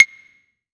LCLAVE 3.wav